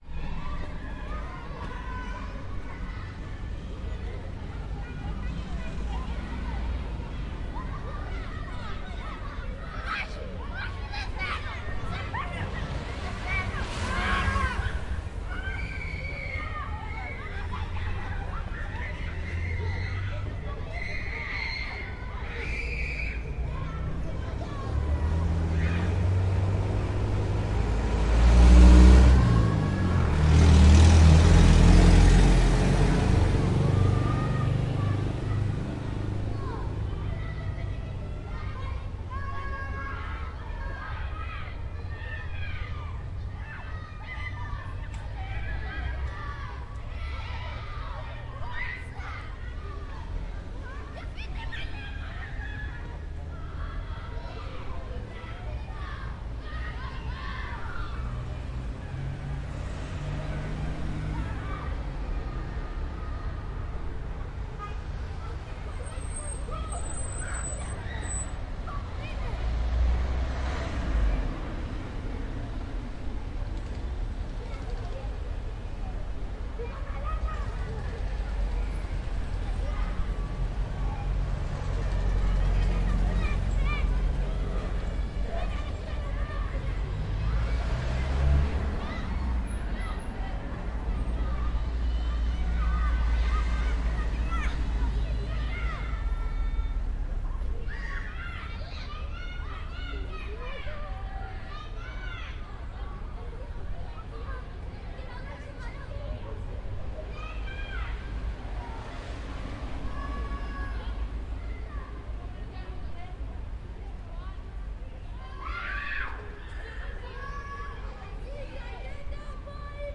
克里特岛 " 学校操场上的孩子们在哭泣
Tag: 播放 尖叫 气氛 操场 学校的院子里 大叫 儿童 现场录音 孩子 学校